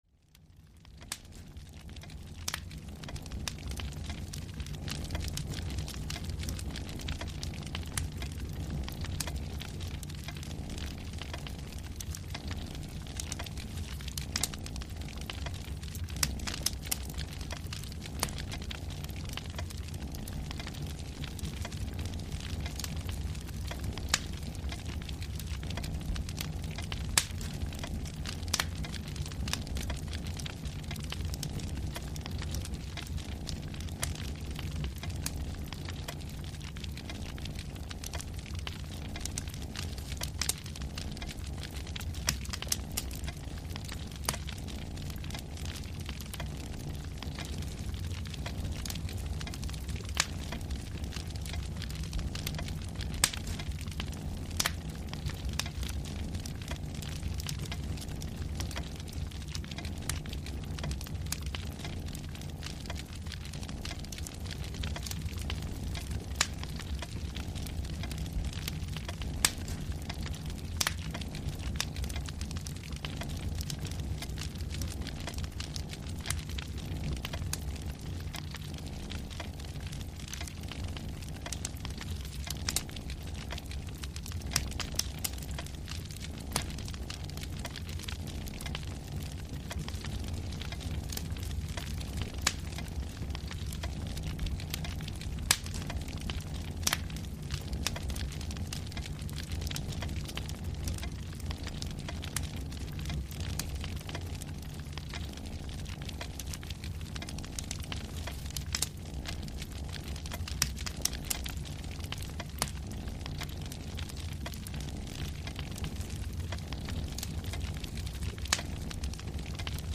The grandfather´s clock is ticking away, and the cat is stretching and purring on the floor.
Saga Sounds is a series of pleasant, varied soundscapes, which you can listen to when winding down, going to sleep or focusing at work. A specially designed sound universe creates a calming atmosphere, also known as "ambience", which you can enter whenever and wherever you want.
ambience-fireside-en-audiokniha